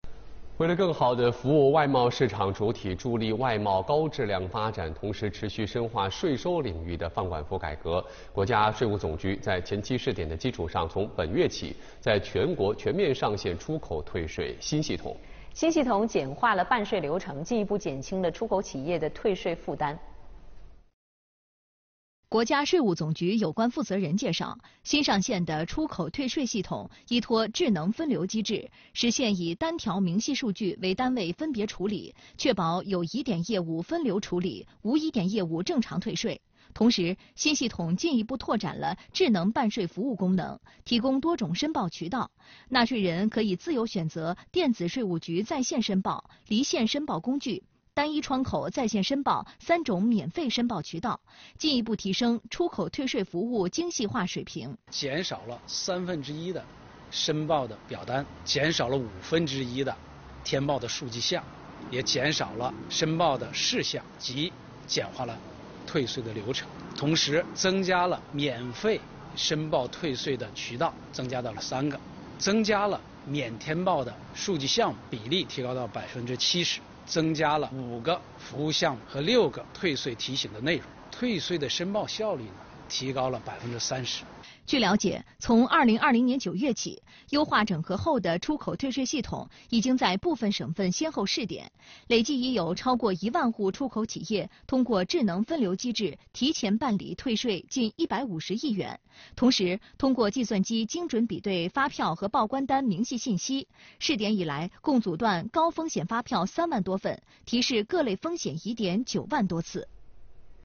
视频来源：央视《朝闻天下》